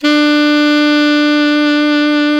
Index of /90_sSampleCDs/Roland L-CDX-03 Disk 1/SAX_Alto Tube/SAX_Alto mp Tube
SAX ALTOMP07.wav